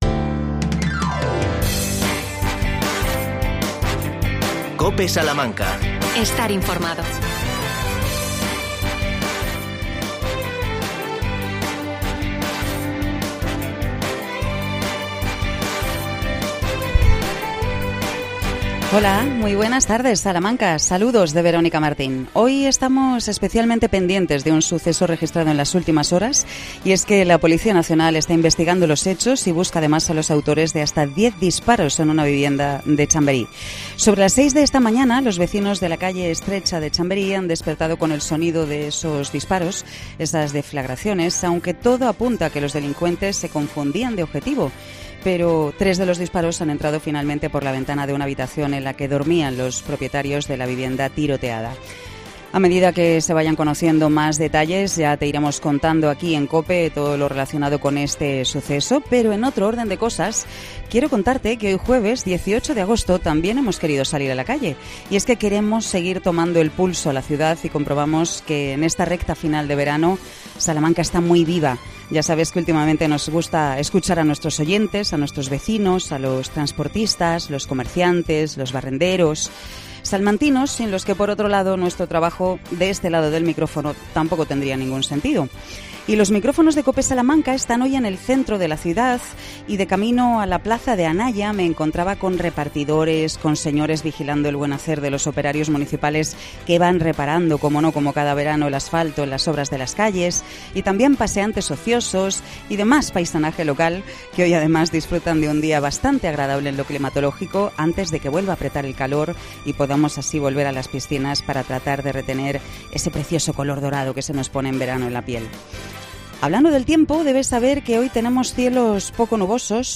AUDIO: ¿Qué se llevan de recuerdos los turistas de Salamanca?. Te lo contamos desde la plaza de Anaya.